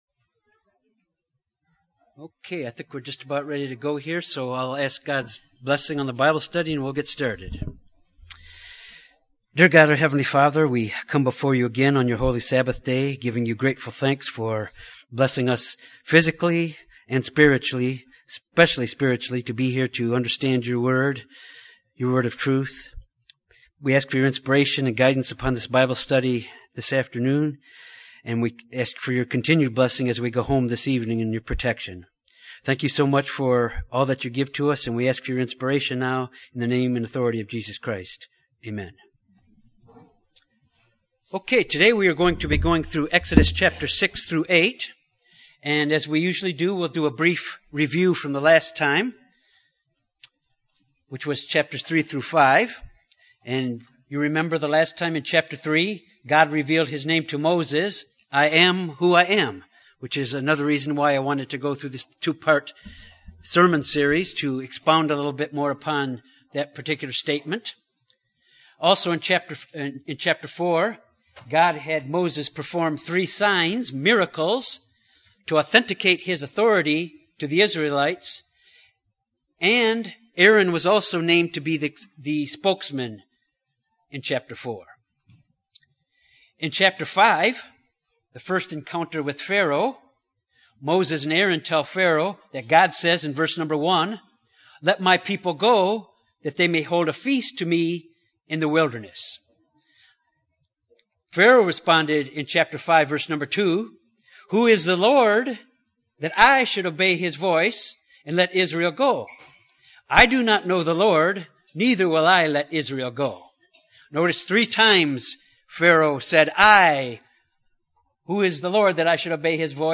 This bible study deals with God’s command to Moses and Aaron for the children of Israel and for Pharaoh to bring the children of Israel out of Egypt. It discusses the first four plagues that were on Egypt to show the Pharaoh that the Lord is God.